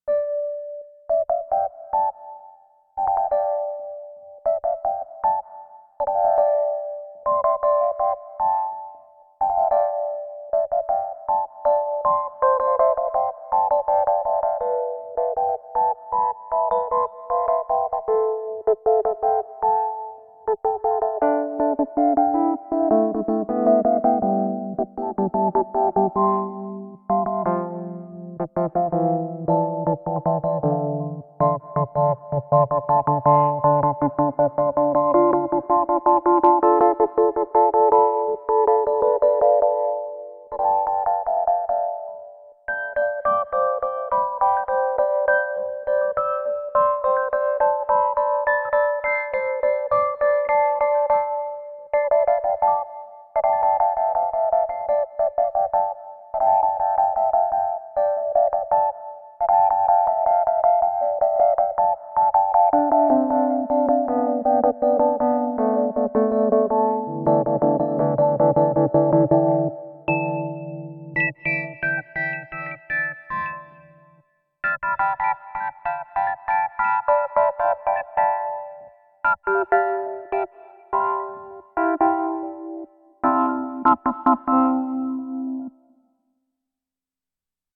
In 32 voice mode, the filter is paraphonic!
Here is some para action on a rhodesy patch with questionable noodling.